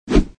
swipe.mp3